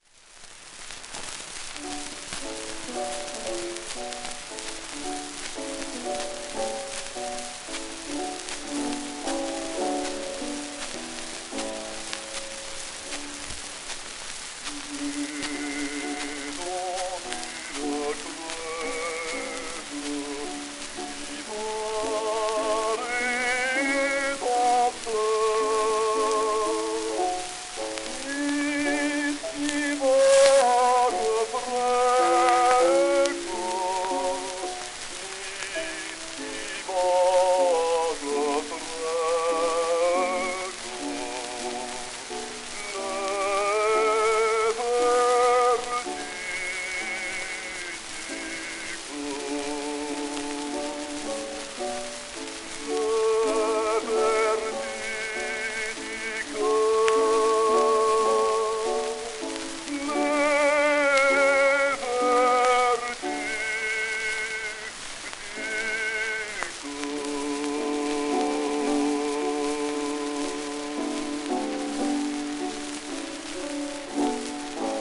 w/ピアノ
1905年録音
19世紀から20世紀初頭にかけて人気のあったフランスのオペラ歌手。
旧 旧吹込みの略、電気録音以前の機械式録音盤（ラッパ吹込み）